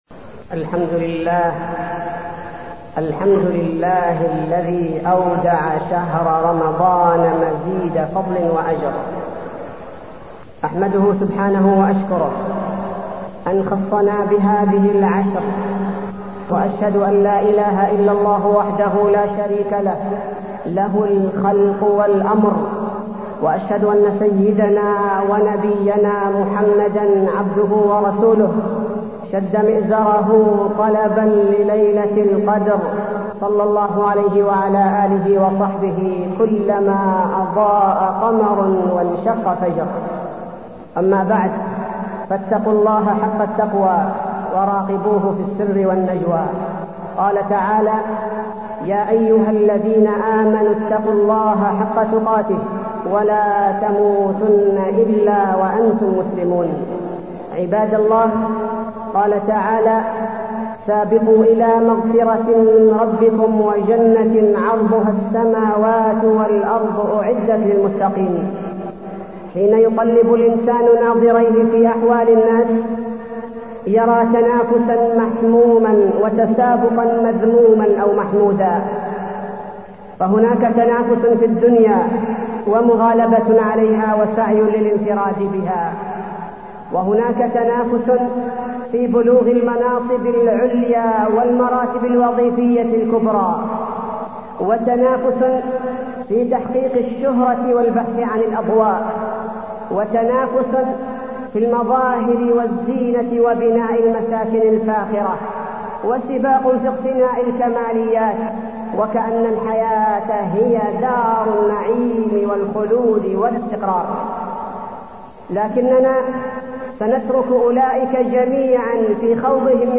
تاريخ النشر ١٩ رمضان ١٤٢١ هـ المكان: المسجد النبوي الشيخ: فضيلة الشيخ عبدالباري الثبيتي فضيلة الشيخ عبدالباري الثبيتي المسابقة في الطاعات وفضل العشر الأواخر